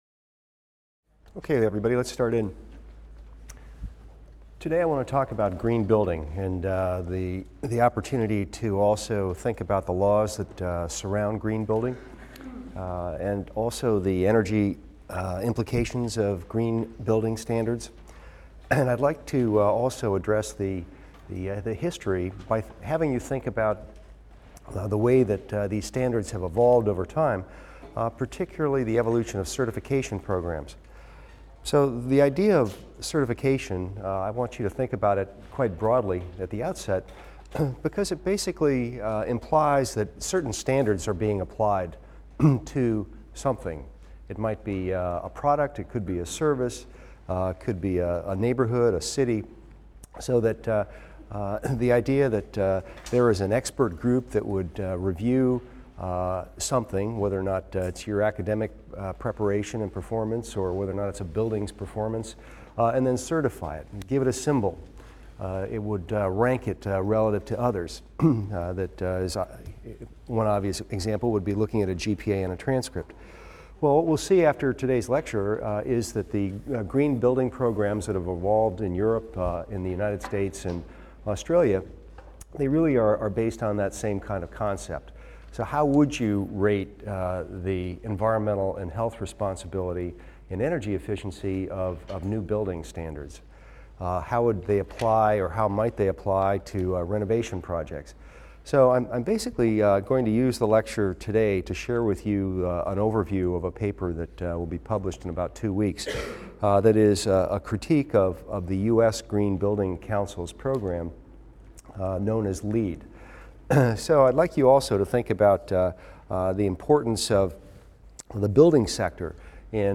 EVST 255 - Lecture 21 - Certification: Design and Green Architecture | Open Yale Courses